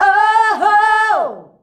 OOOHOO  A.wav